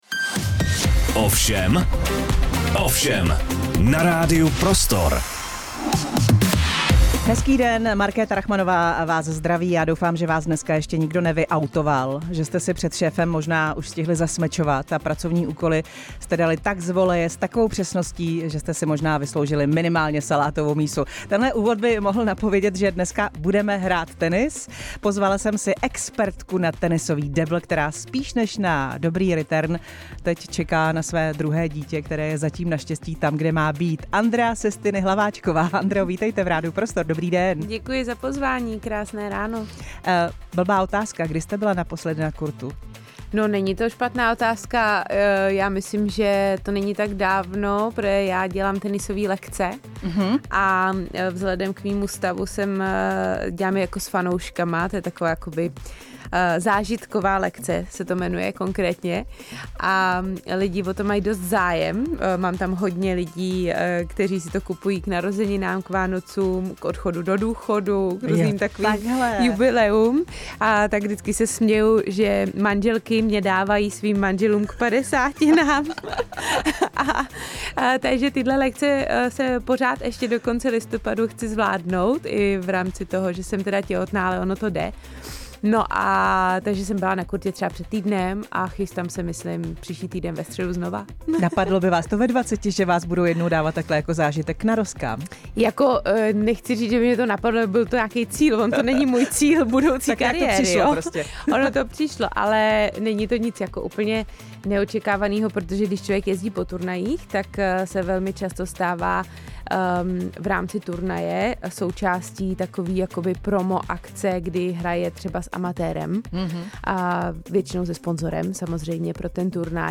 Poslechněte si rozhovor O všem s Andreou Sestini Hlaváčkovou.
Hostem pořadu O všem byla Andrea Sestini Hlaváčková